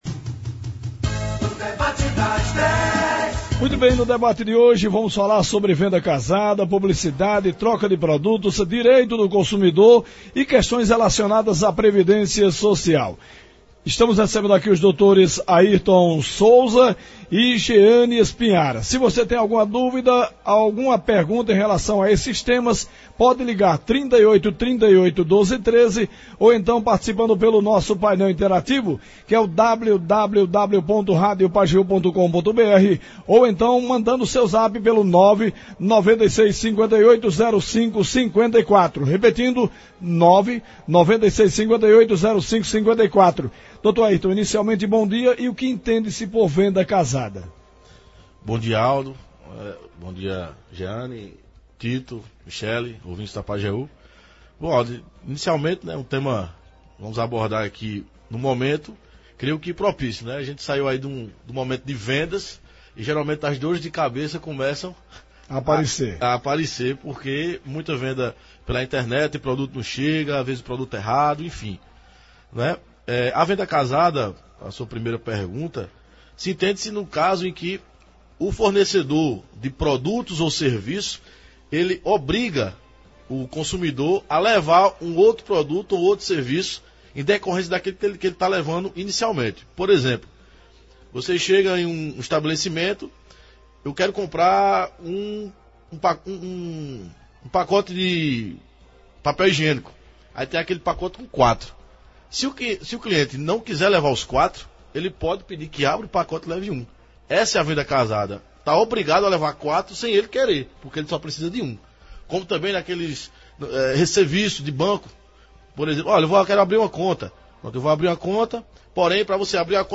A entrevista foi muito elucidativa, os ouvintes da Pajeú e internautas puderam tirar várias dúvidas sobre os temas abordados. A grande maioria dos questionamentos foi em relação a Previdência Social.